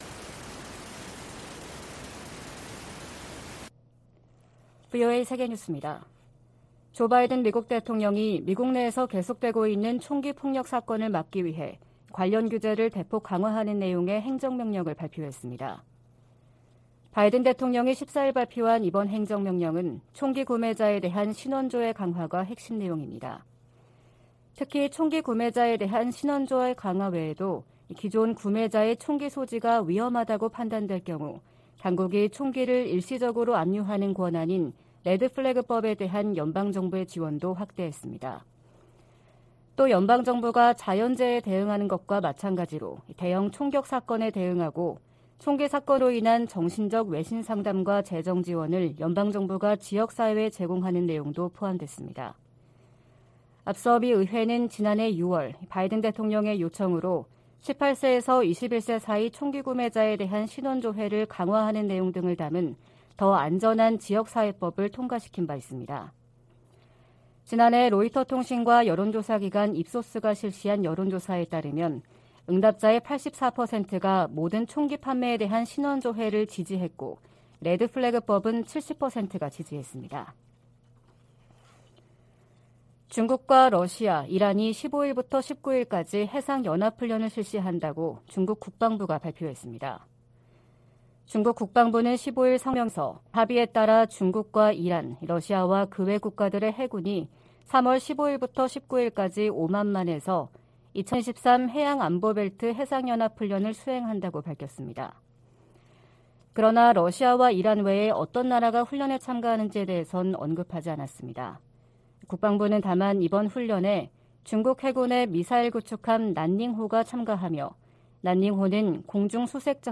VOA 한국어 '출발 뉴스 쇼', 2023년 3월 16일 방송입니다. 북한은 14일 황해남도 장연에서 지대지 탄도미사일 2발 사격 훈련을 실시했다고 다음날 관영매체를 통해 발표했습니다. 북한의 최근 미사일 도발이 미한 연합훈련을 방해할 의도라면 성공하지 못할 것이라고 미 백악관이 지적했습니다. 일본 방문을 앞둔 윤석열 한국 대통령은 일본 언론과의 인터뷰에서 북한 핵 위협에 맞서 미한일 협력의 중요성을 강조했습니다.